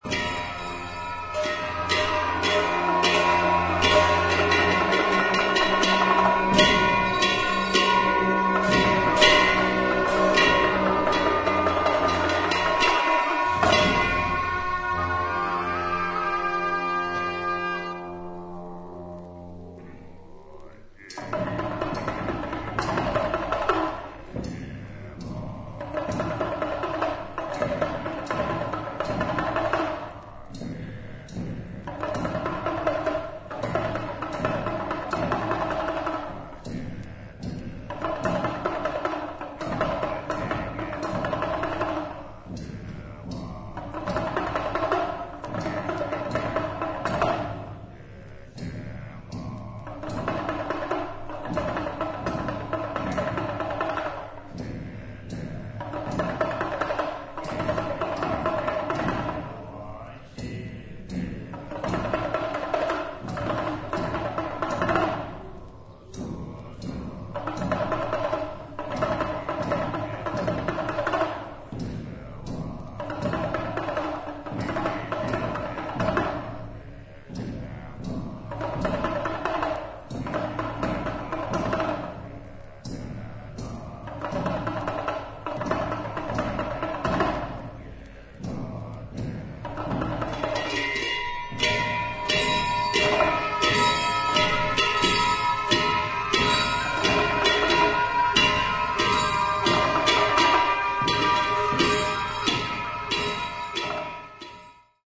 本作は、ブータンの伝統的な宗派「ドゥプカ派」の儀式に焦点を当て、総勢76人のラマ僧と僧侶が神聖な寺院で長いトランペット、ショーム、シンバル、太鼓などのチベット楽器を用いて詠唱し、演奏。臨場感あふれる声明や打楽器の演奏、メディテーティブな僧院音楽が生々しく記録された大変貴重な音源です。